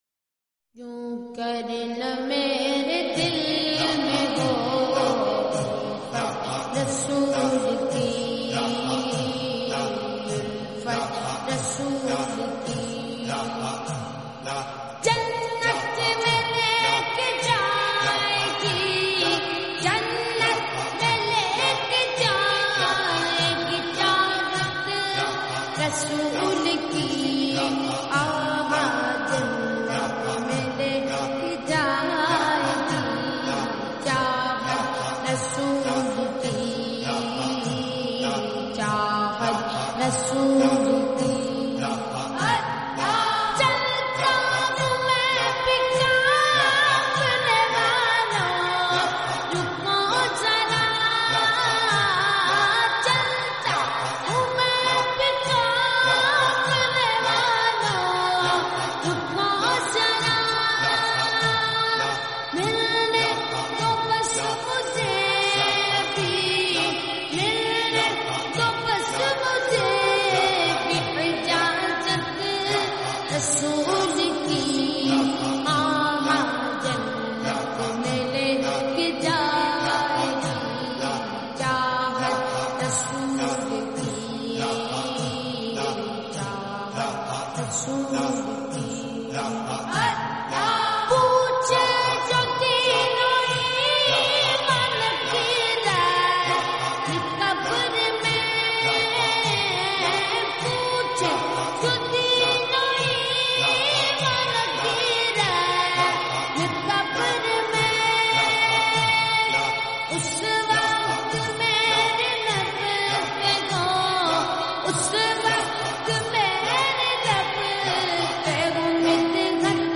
FULL NAAT (SLOW+REVERB)